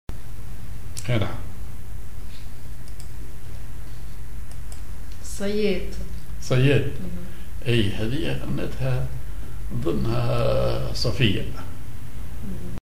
Maqam ar بياتي
genre أغنية